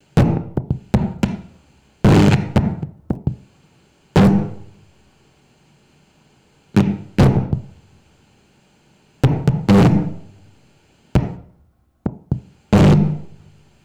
Efecto de mala conexión de un micrófono
Sonidos: Especiales